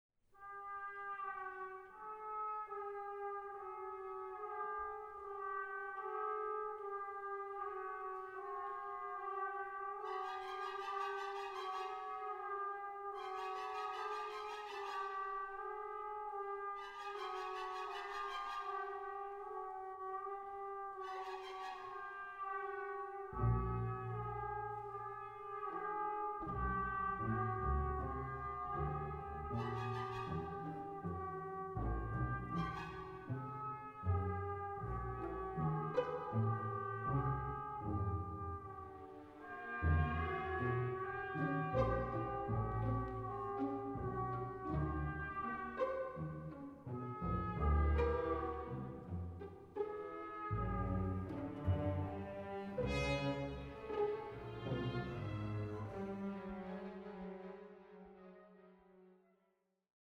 for Brass, Timpani and Strings